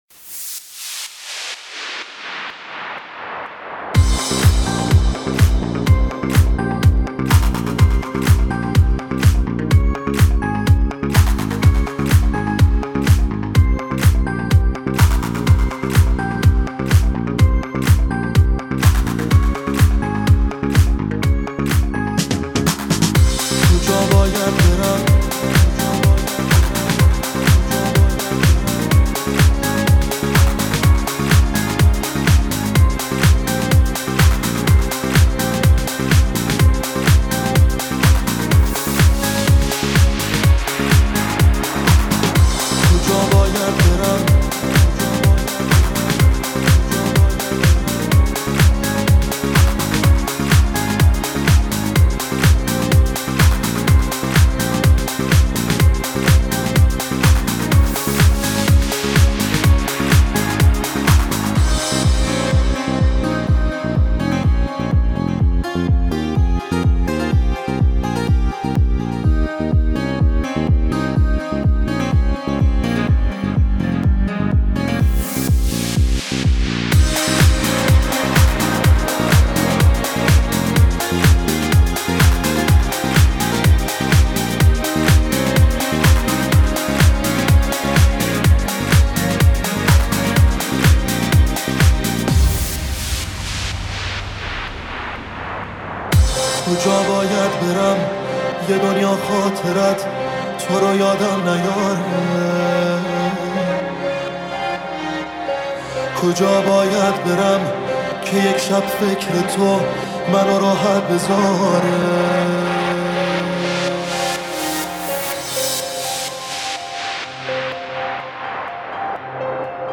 ریمیکس اول